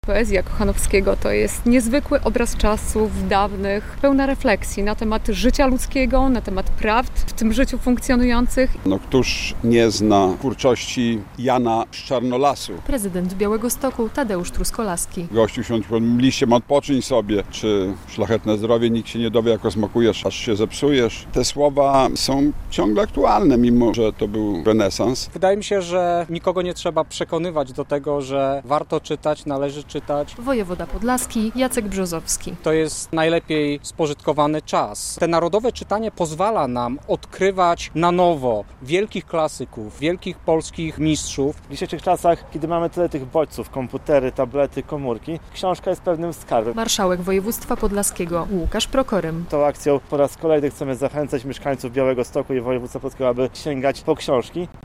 Narodowe Czytanie w Różance - relacja